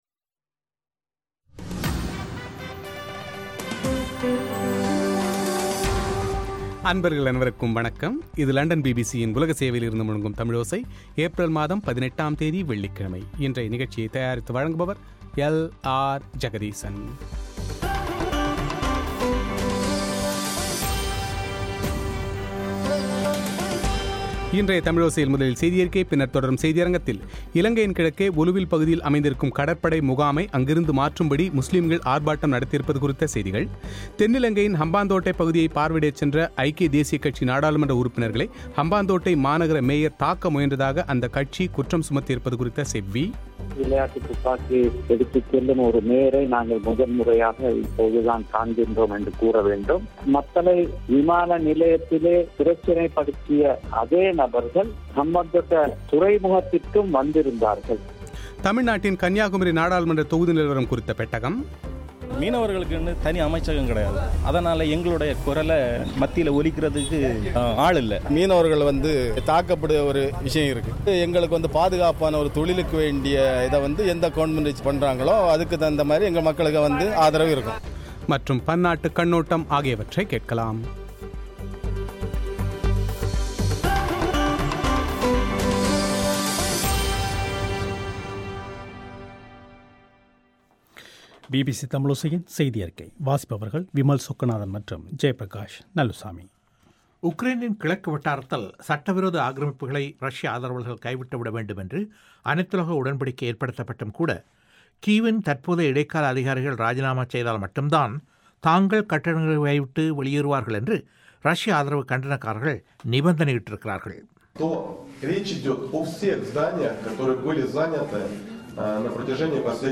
தென்னிலங்கையின் ஹம்பாந்தோட்டை பகுதியை பார்வையிடச் சென்ற ஐக்கிய தேசியக் கட்சி நாடாளுமன்ற உறுப்பினர்களை ஹம்பாந்தோட்டை மாநகர மேயர் தாக்க முயன்றதாக அந்த கட்சி குற்றம் சாட்டியிருப்பது குறித்த செய்திகள் மற்றும் இந்த குற்றச்சாட்டுக்களை மறுக்கும் மேயரின் செவ்வி;